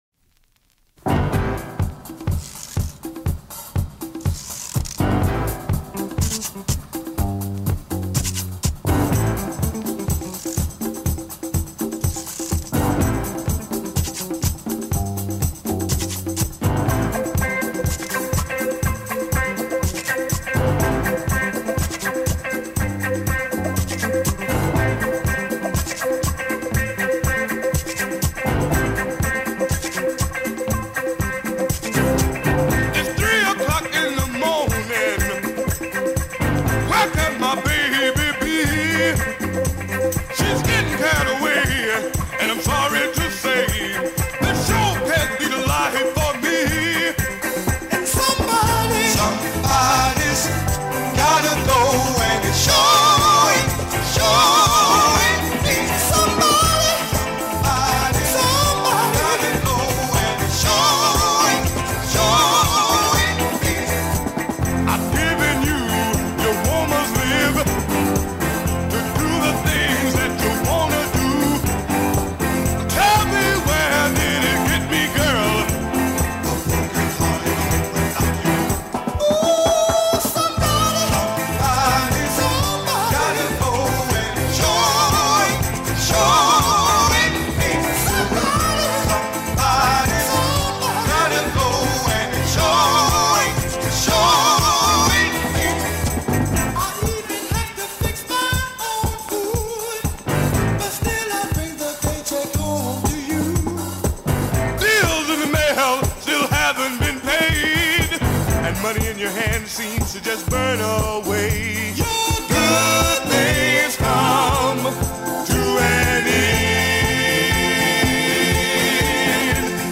7″ inch vinyl
This is our kind of disco music!